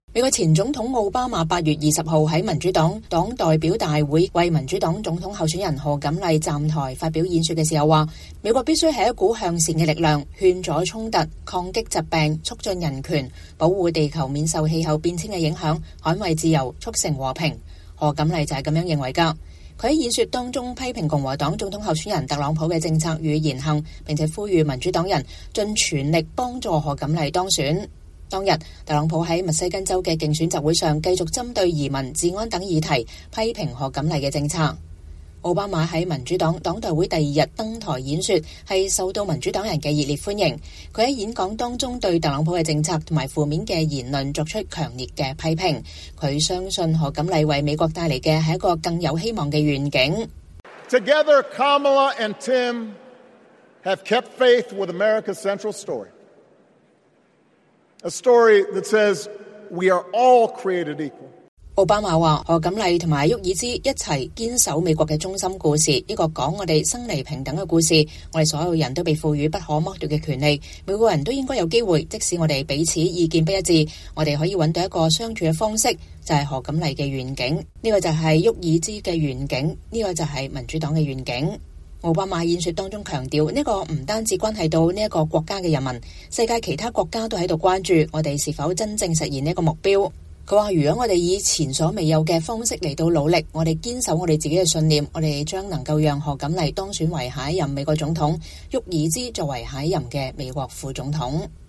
美國前總統奧巴馬8月20日在民主黨黨代會隔天晚上發表演講，為民主黨總統候選人賀錦麗 (又譯卡瑪拉‧哈里斯)站台發表演説時說，“美國必須是股向善的力量：勸阻衝突，抗擊疾病，促進人權，保護地球免受氣候變遷的影響，捍衛自由，促成和平。賀錦麗就是這麼認為的”。
奧巴馬在民主黨黨代會第二日登台演説受到民主黨人的熱烈歡迎。